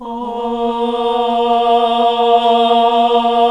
AAH A#1 -R.wav